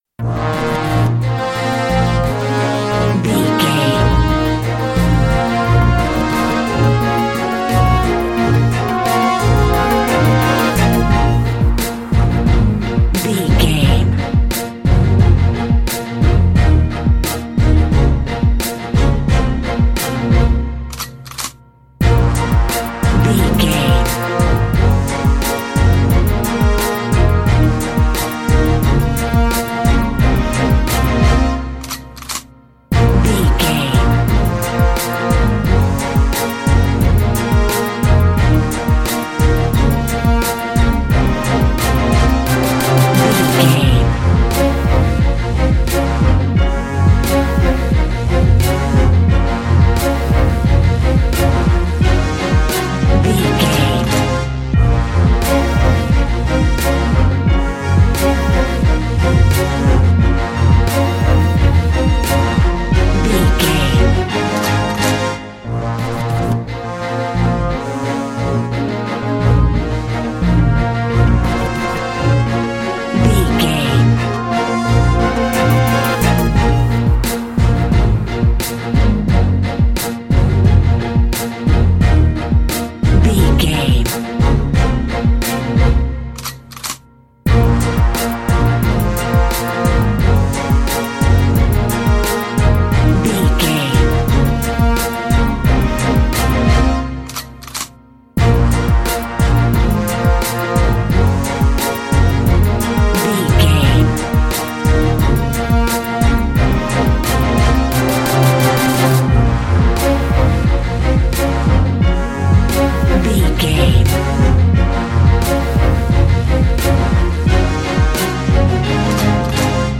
Fast paced
In-crescendo
Aeolian/Minor
B♭
strings
drums
horns